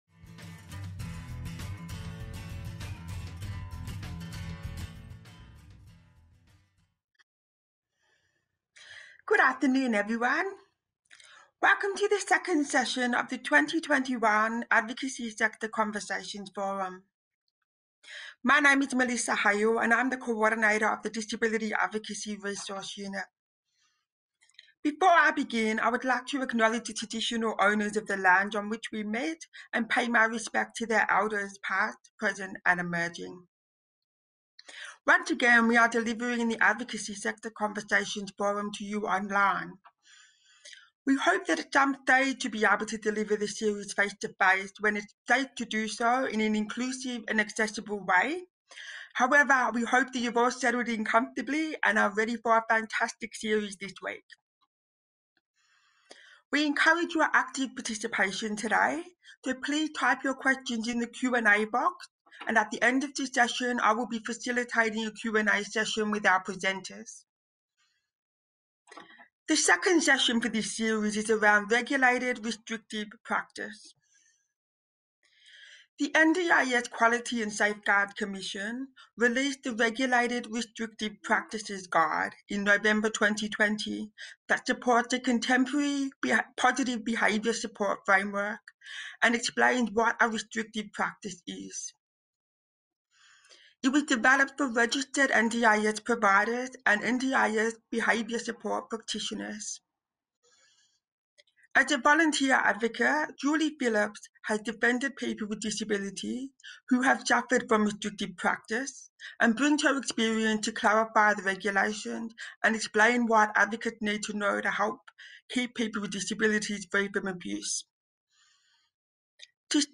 This session was part of the Advocacy Sector Conversations Forum series held on Zoom on 3 March 2021.